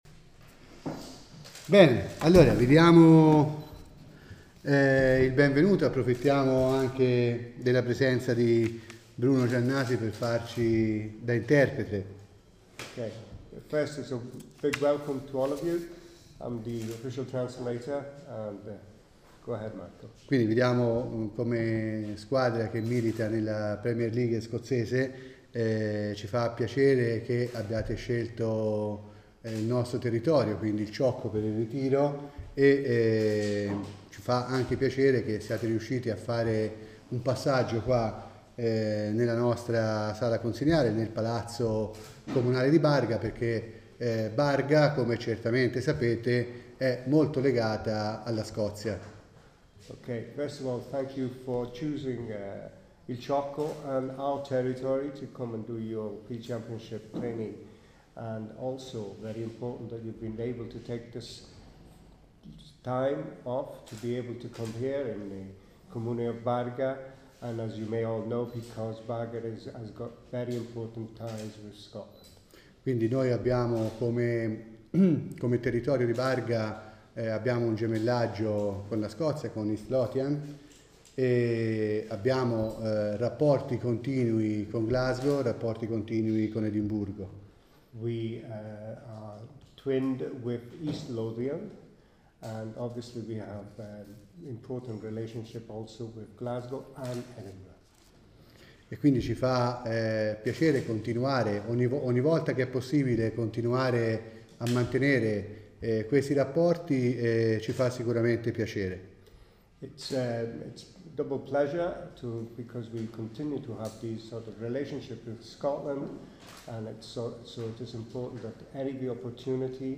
Click on the link below to hear the Mayor of Barga Marco Bonini welcoming the team to the Palazzo Pancrazi in Barga Vecchia this morning